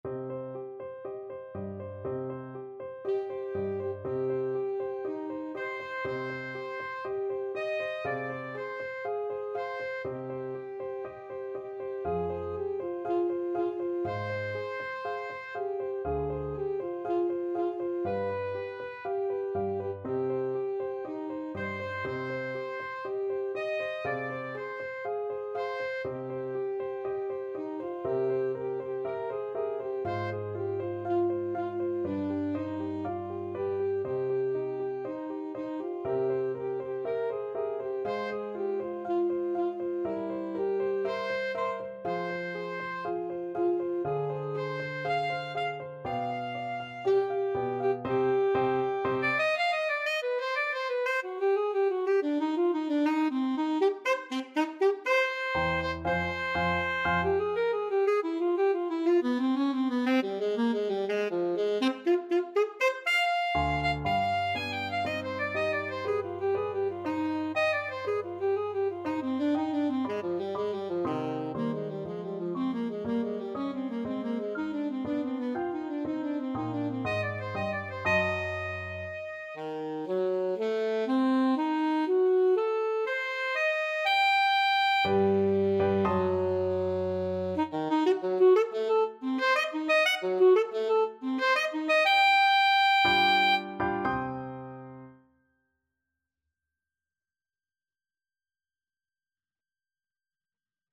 Alto Saxophone
Allegro, molto appassionato (View more music marked Allegro)
2/2 (View more 2/2 Music)
Classical (View more Classical Saxophone Music)